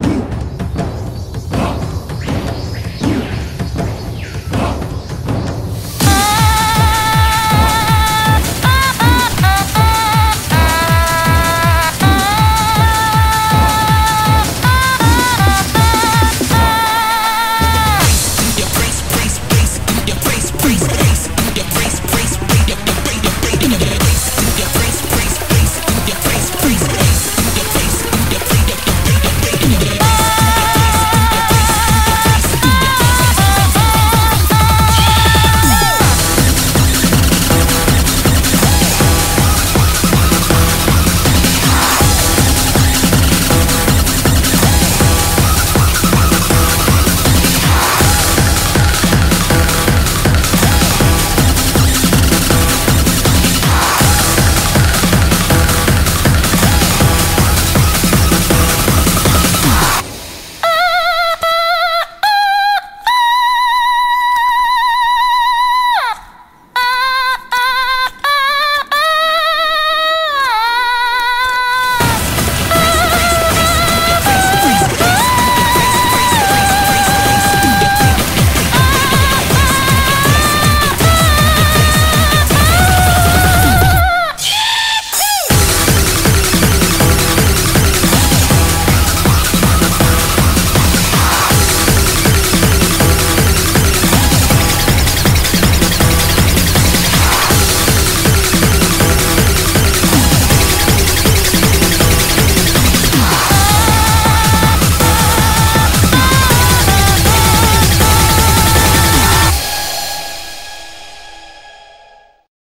BPM160